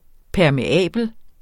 Udtale [ pæɐ̯meˈæˀbəl ]